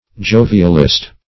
Jovialist \Jo"vi*al*ist\